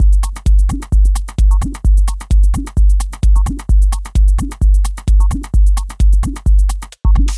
技术循环2 130 BPM
可用于techno、tech house或electro。
Tag: 130 bpm Techno Loops Drum Loops 1.24 MB wav Key : Unknown